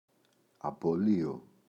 απολύω [apo’lio]